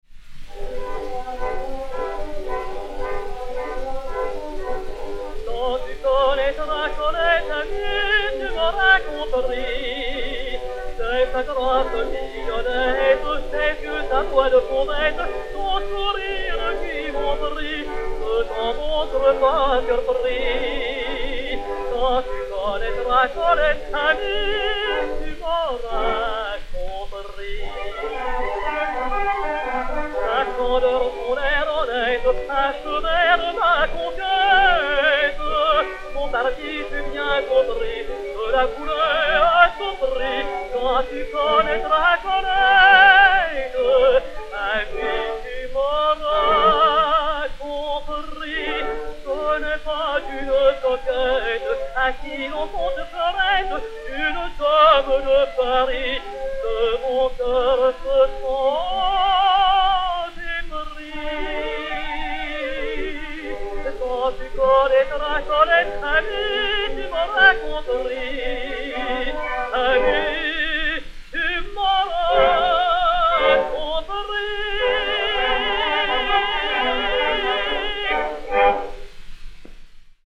Edmond Clément (Clément) et Orchestre